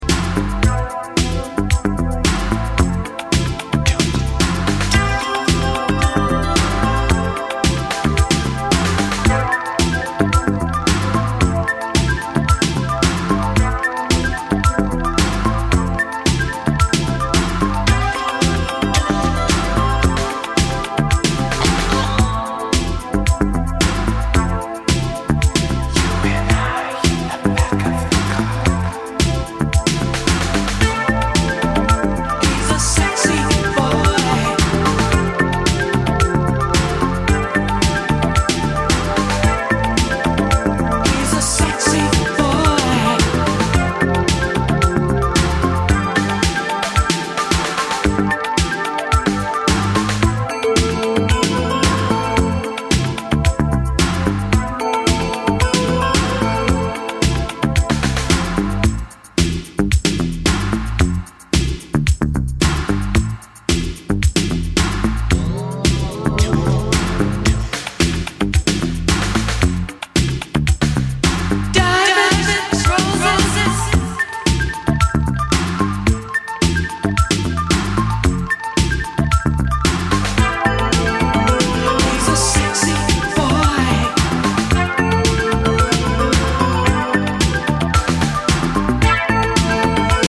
” a steamy Canadian pop gem.
Balearic Synth